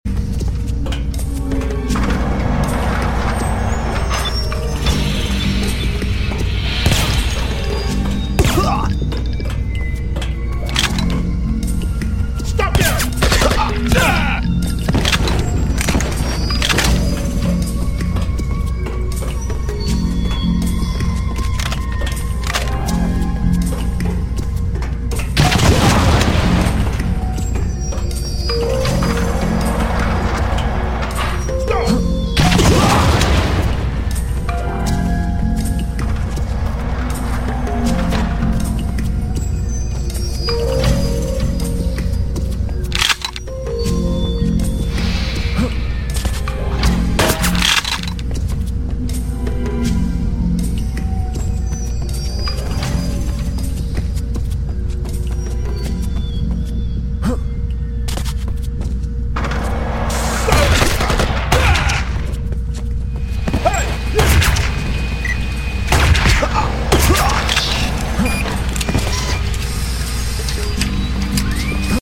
Useless Pistol Mp3 Sound Effect